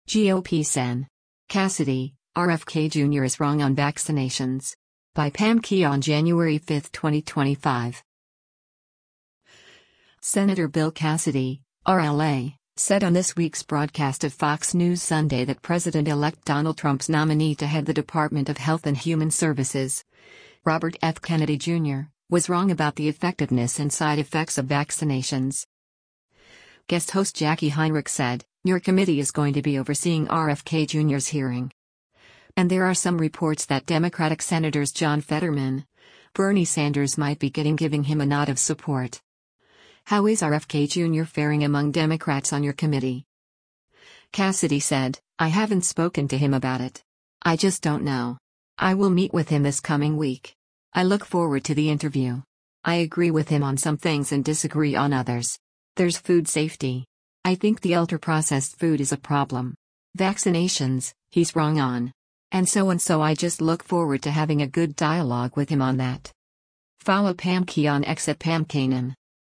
Senator Bill Cassidy (R-LA) said on this week’s broadcast of “Fox News Sunday” that President-elect Donald Trump’s nominee to head the Department of Health and Human Services, Robert F. Kennedy Jr., was “wrong” about the effectiveness and side effects of vaccinations.